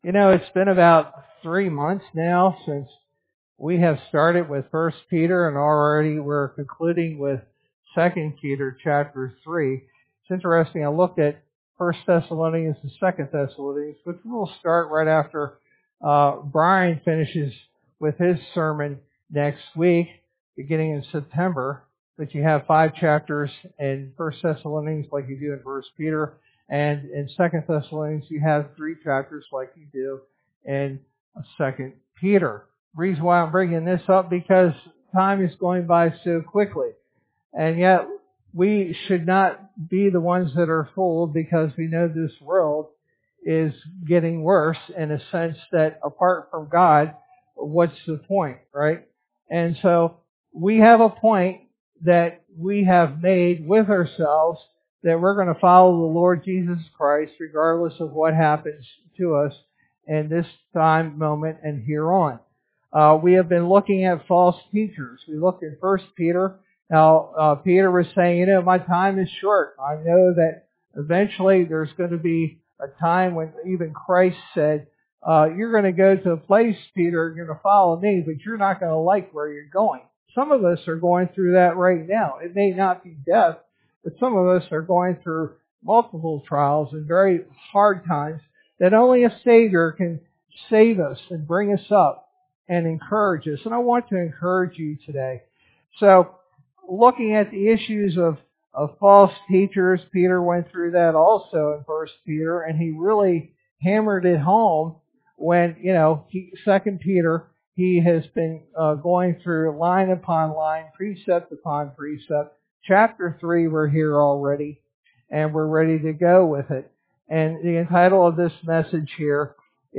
Sermon verse: 2 Peter 3:1-18